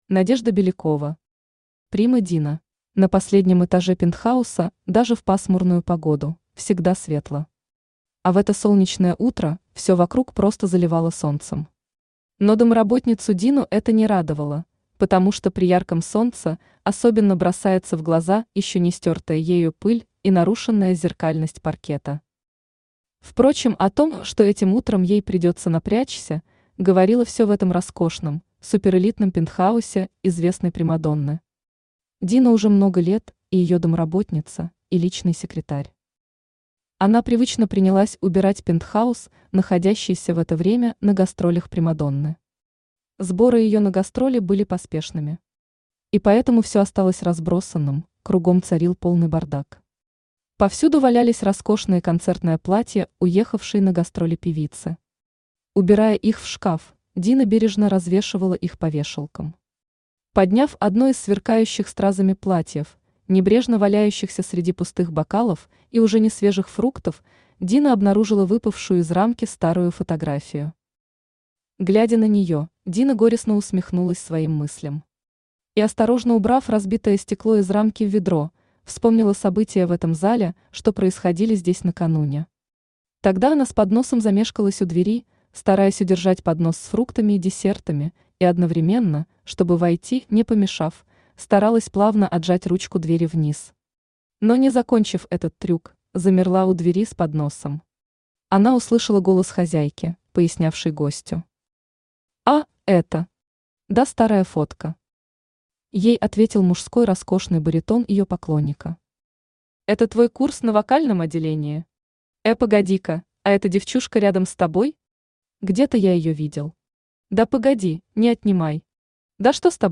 Аудиокнига Прима Дина